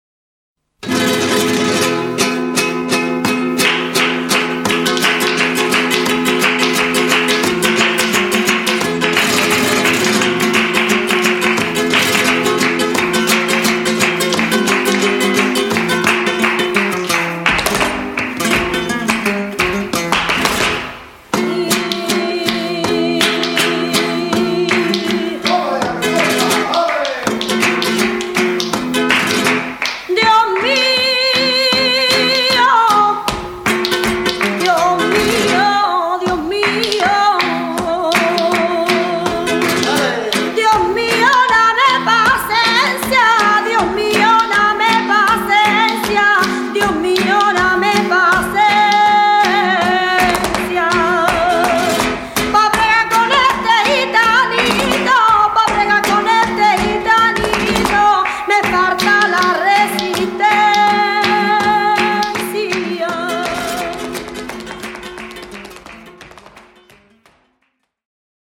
TANGO (